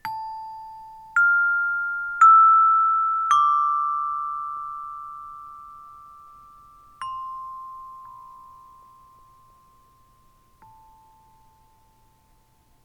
Struck Metal (00:12)
Struck Metal.wav